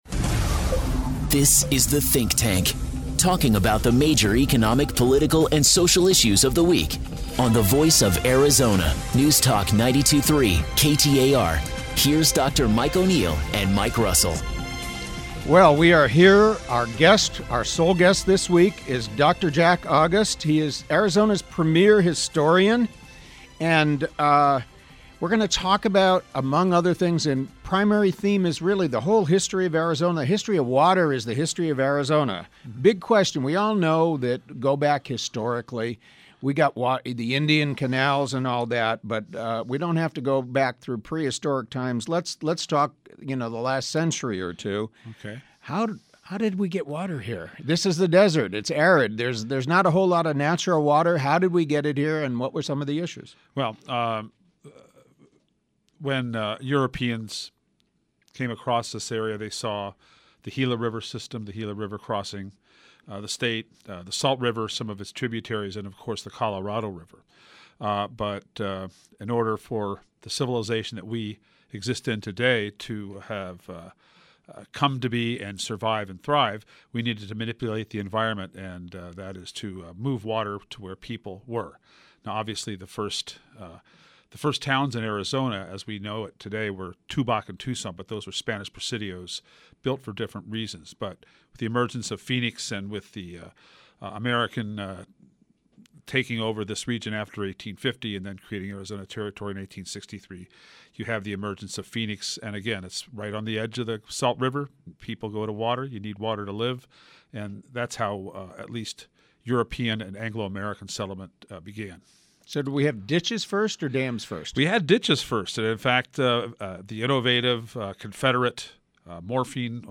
A Weekly Public Affairs and Public Policy Radio Show